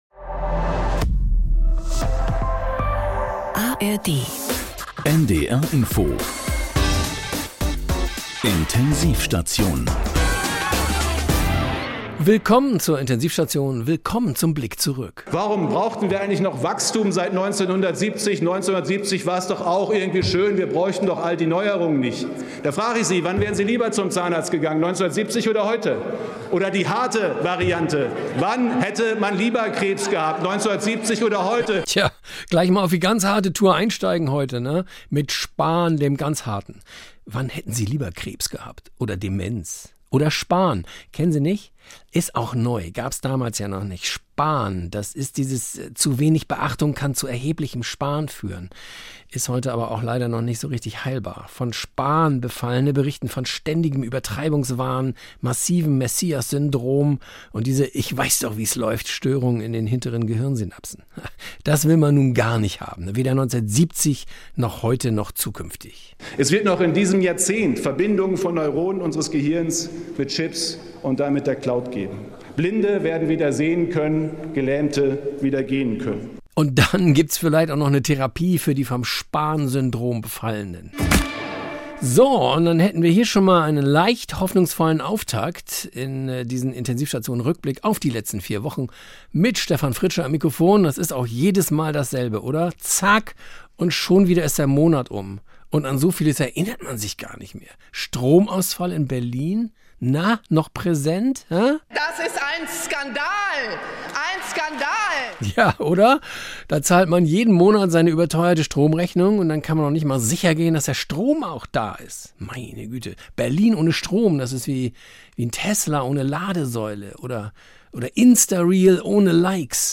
… continue reading 327集单集 # Saubere Komödien # Komödien Neuigkeiten # NDR Info # Komödie # Unterhaltung